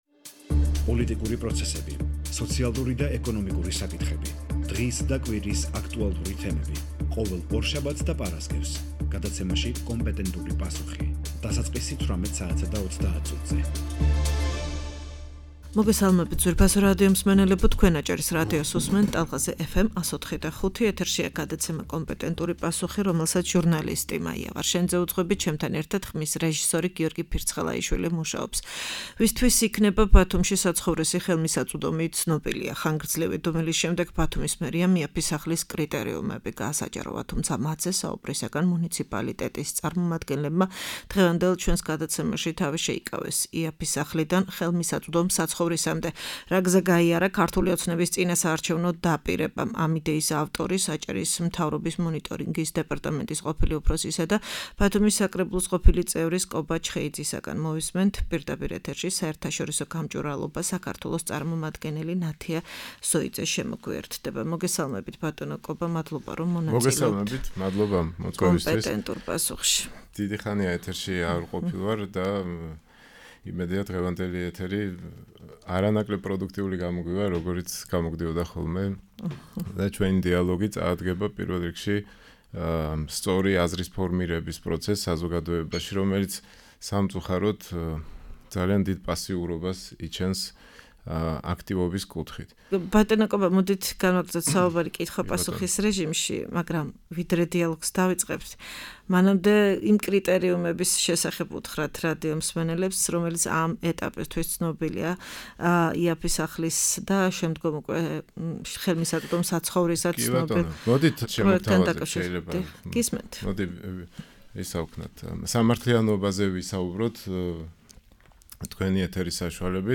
„იაფი სახლიდან“ „ ხელმისაწვდომ საცხოვრისამდე“- რა გზა გაიარა ქართული ოცნების წინასაარჩევნო დაპირებამ -ამ თემაზე აჭარის მთავრობის მონიტორინგის დეპარტამენტის ყოფილი უფროსი და ბათუმის საკრებულოს ყოფილი წევრი კობა ჩხეიძე საუბრობს.